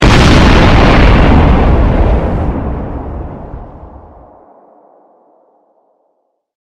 Explosion
attack bomb cannon distruction explode explosion firearm gun sound effect free sound royalty free Voices